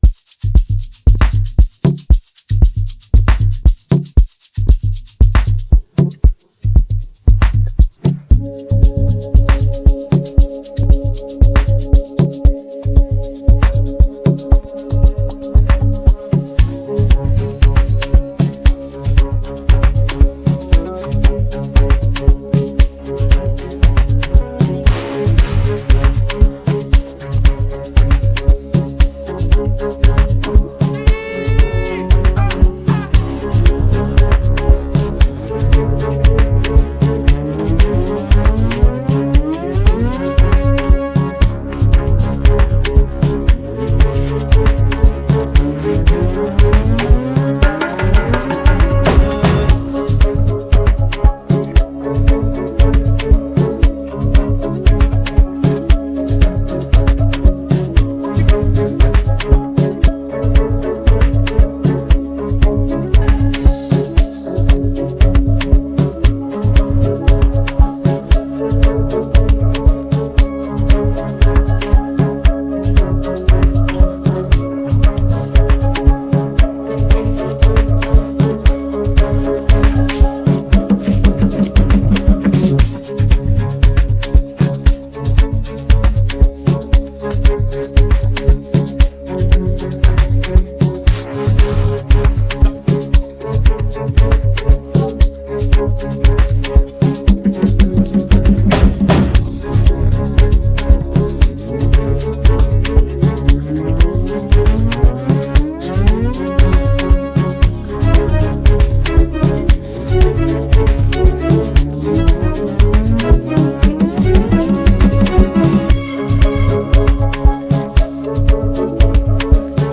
Afro popAfrobeatsAmapaino